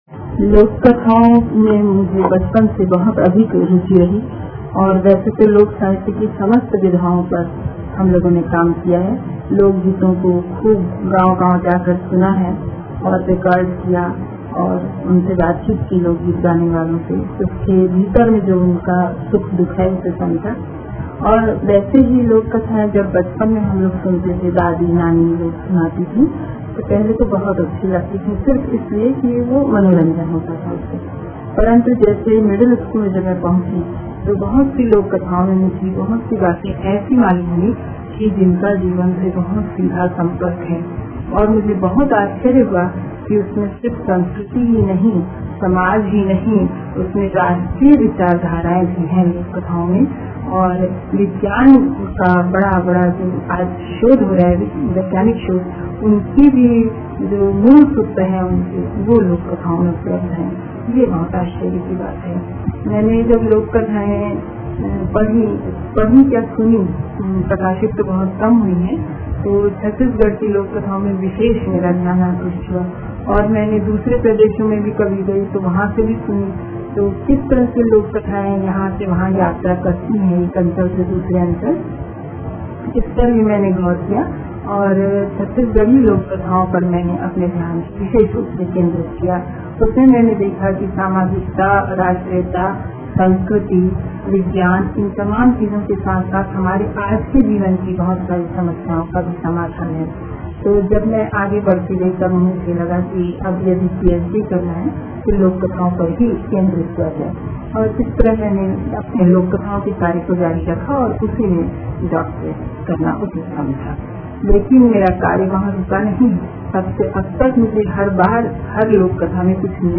बल्कि गहरा अध्ययन भी की है - उनके साथ एक साक्षातकार